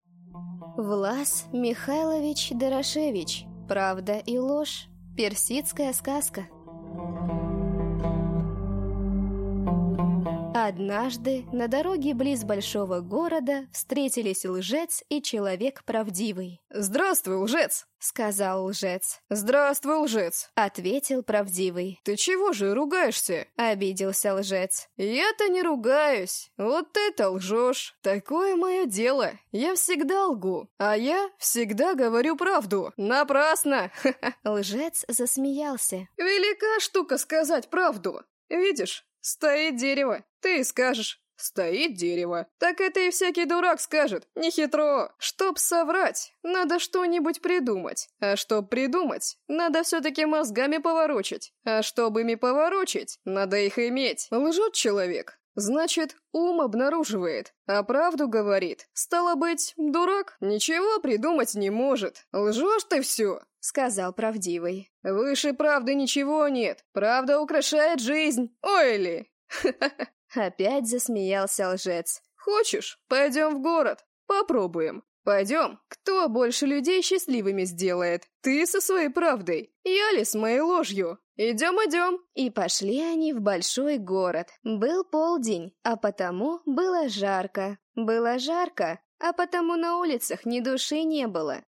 Аудиокнига Правда и ложь | Библиотека аудиокниг